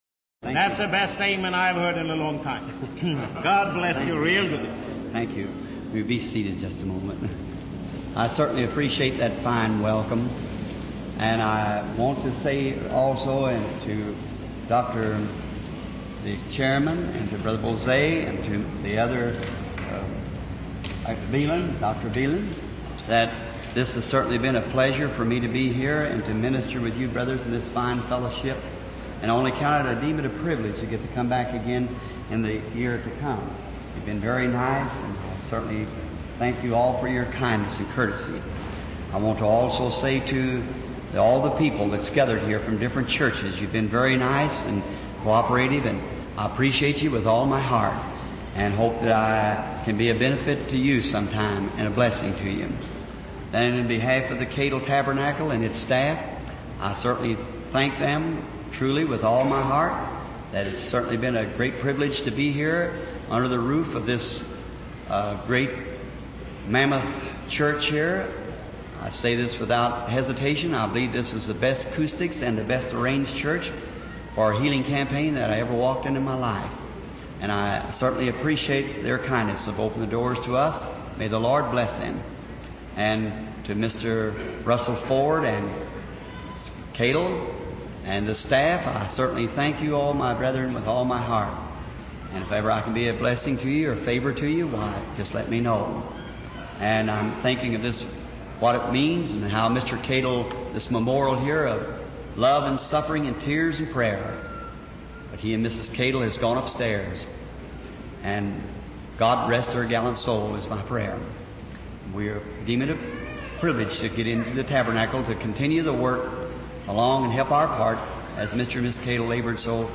Dieses Portal gibt Ihnen die Möglichkeit, die ca. 1200 aufgezeichneten Predigten